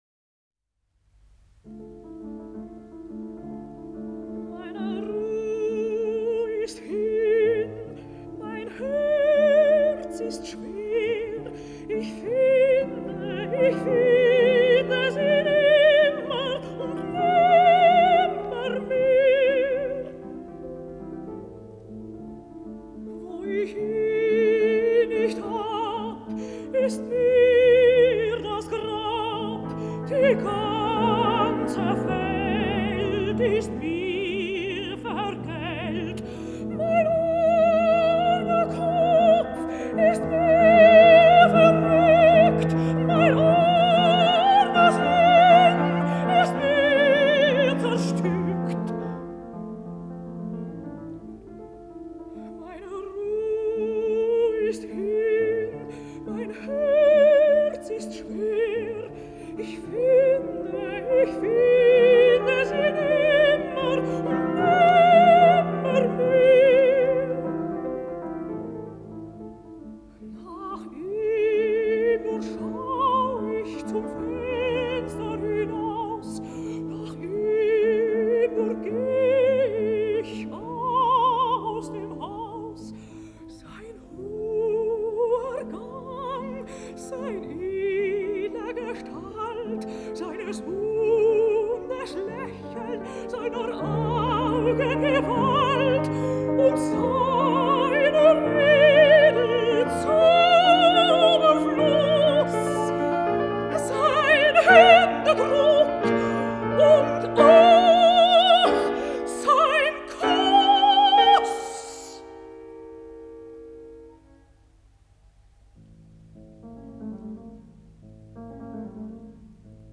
《纺车旁的格丽卿》采用了分节歌的形式。
曲子是八六拍的。